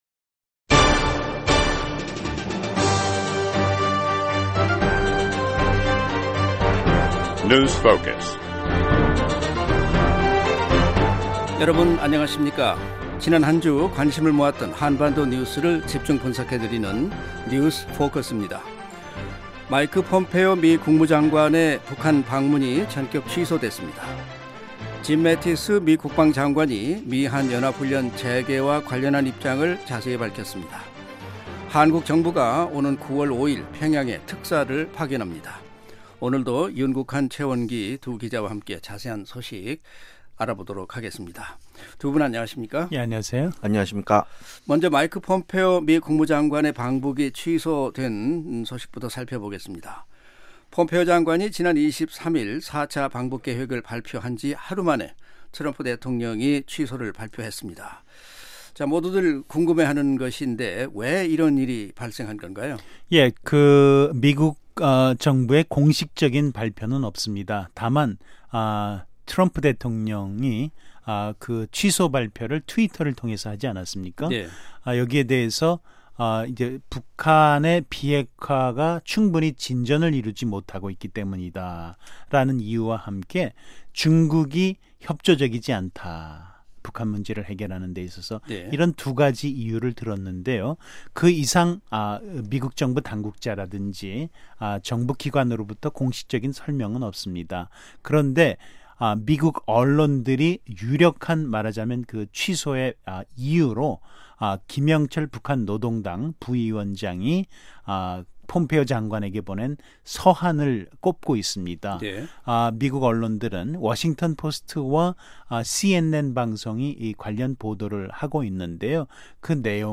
지난 한 주 관심을 모았던 한반도 뉴스를 집중 분석해 드리는 뉴스 포커스입니다. 마이크 폼페오 미국 국무장관의 북한 방문이 전격 취소됐습니다. 짐 매티스 미 국방장관이 미-한 연합훈련 재개와 관련한 입장을 자세히 밝혔습니다. 한국 정부가 오는 9월5일 평양에 특사를 파견합니다.